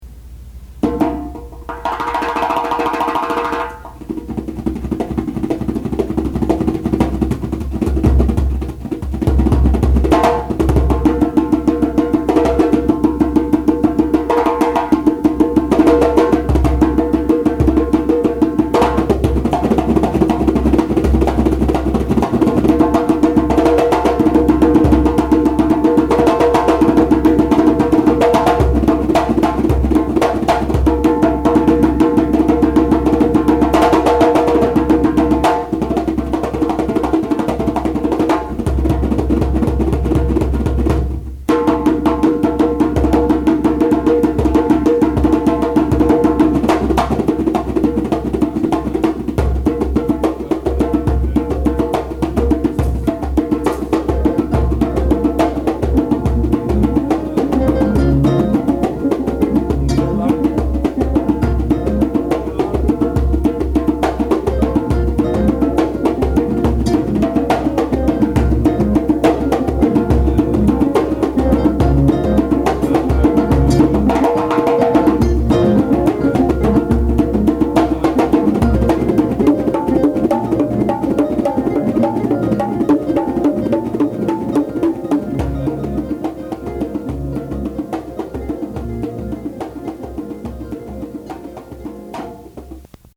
Live recordings from Stetson!
Drum Call at Stetson